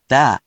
We’re going to show you the character, then you you can click the play button to hear QUIZBO™ sound it out for you.
In romaji, 「だ is transliterated as「da」which sounds sort of like 「dahh」**